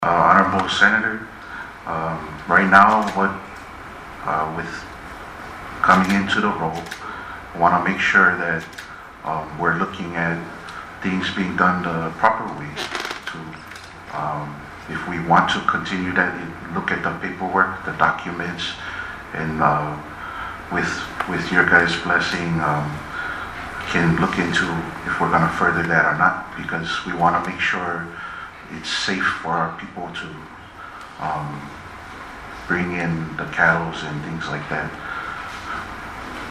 Confirmation hearing for Daniel Helsham
The director nominee gave this reply…